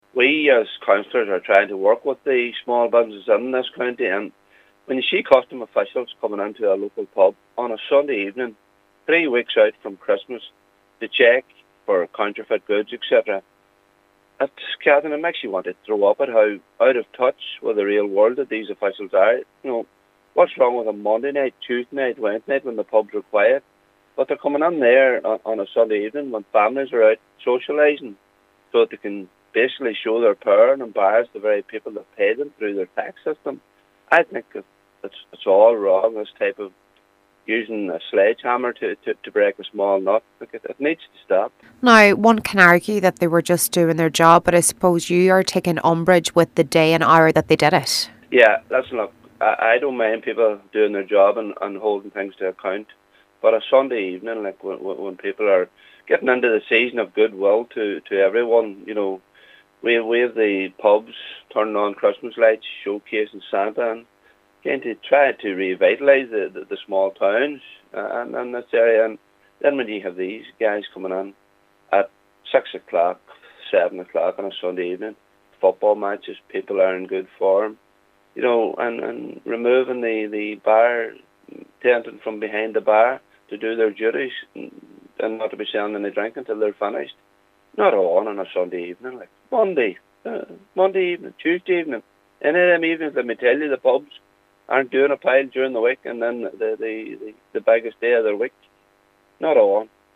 He says what happened on Sunday was an affront to that service: